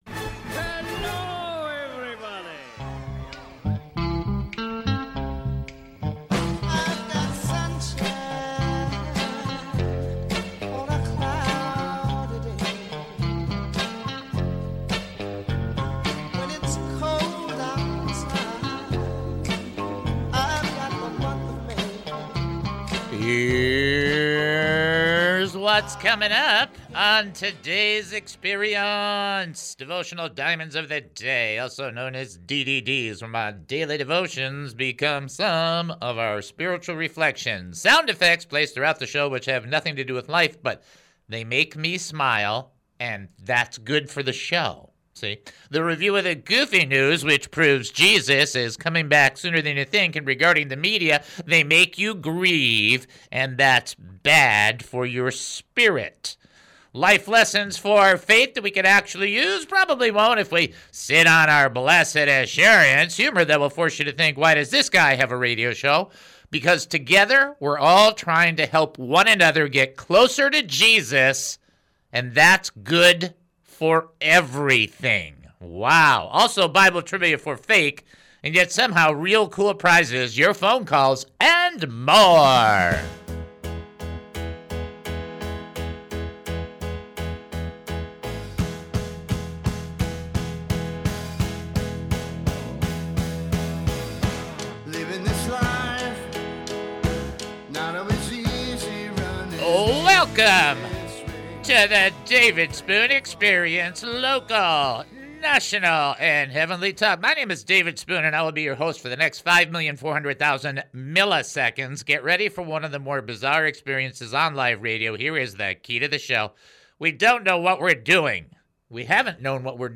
What a remarkable return to the studio after a few days off!
We had the privilege of a wonderful brother calling in to share one of the areas he desires to work on, which is overcoming fear.